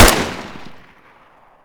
ump45_shoot2.ogg